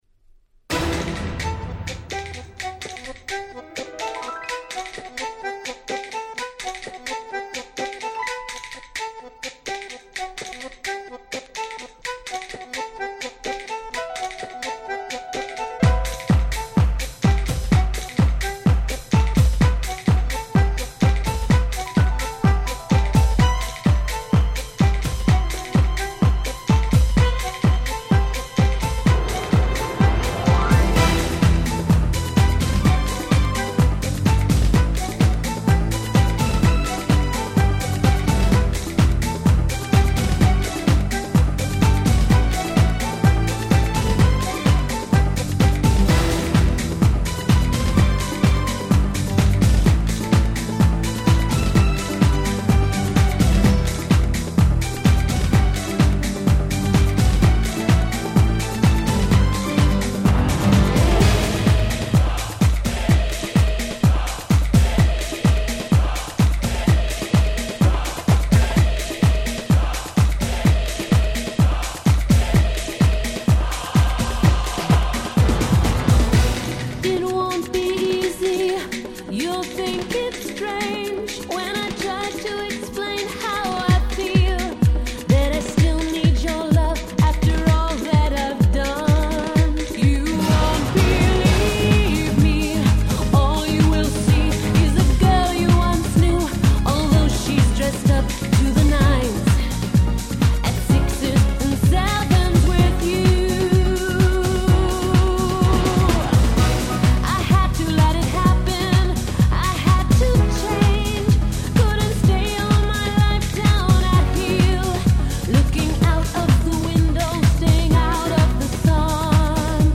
めちゃくちゃキャッチー！